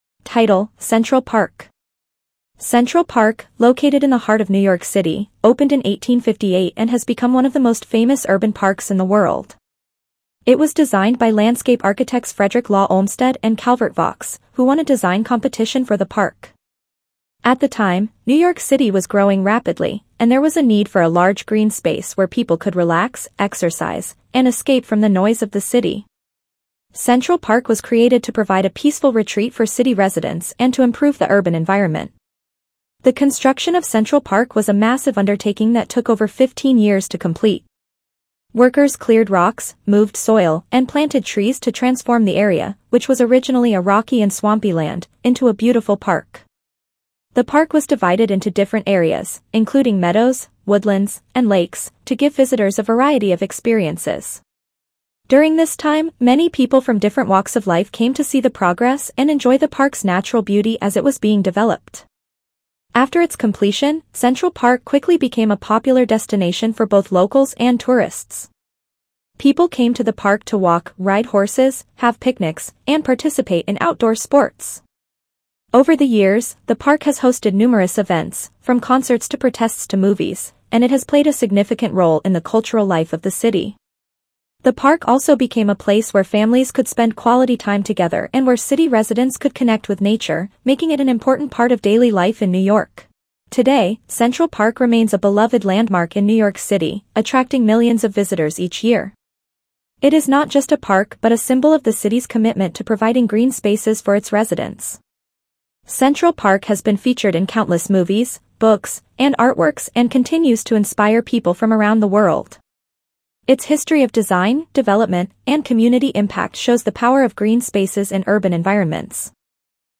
Reading-Lesson-B2-Central-Park.mp3